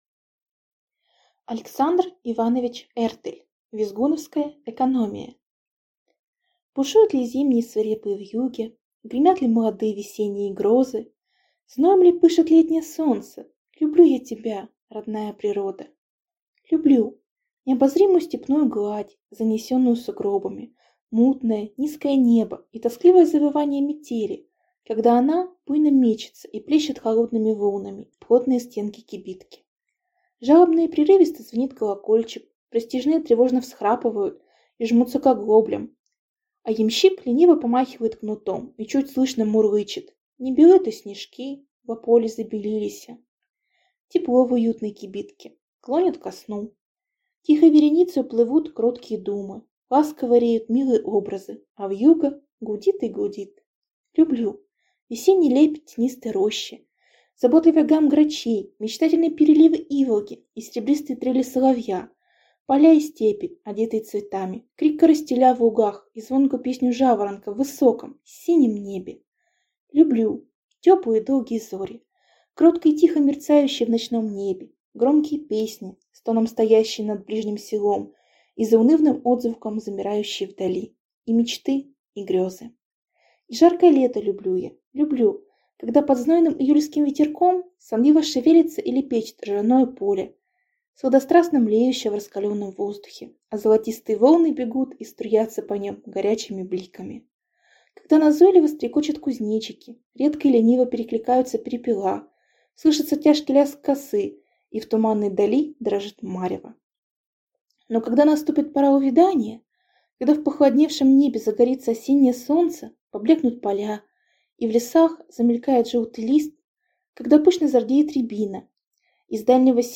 Аудиокнига Визгуновская экономия